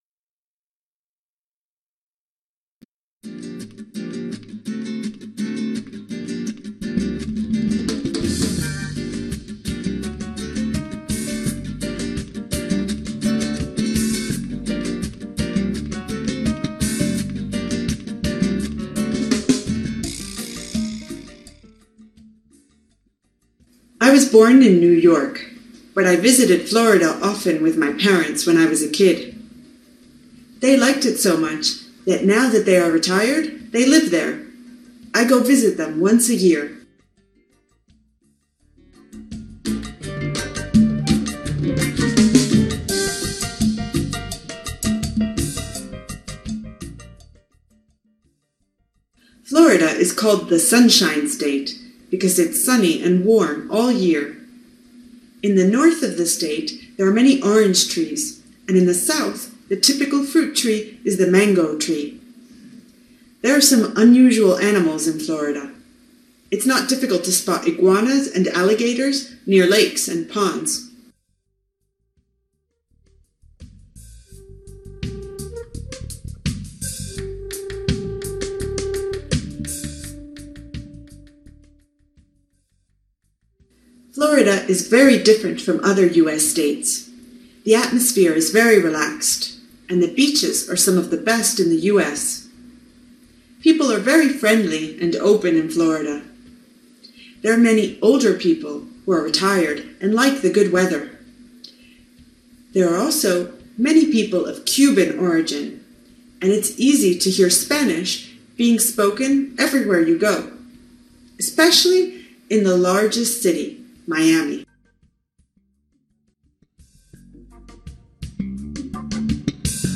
AUDIO: FLORIDA - THE INTERVIEW
FLORIDATHEINTERVIEW.mp3